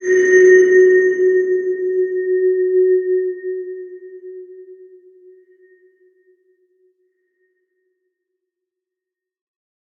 X_BasicBells-F#2-pp.wav